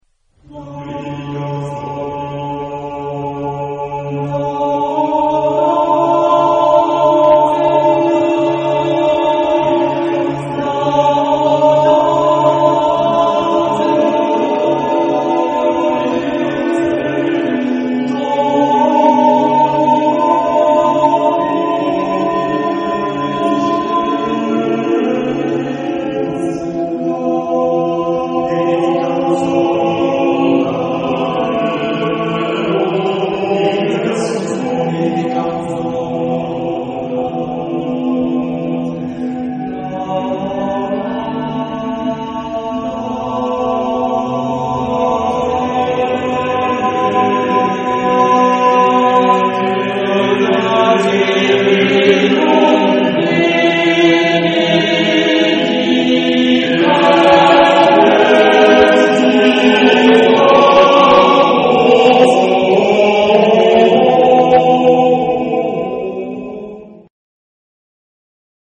Epoque: 20th century
Type of Choir: TTTBB  (5 men voices )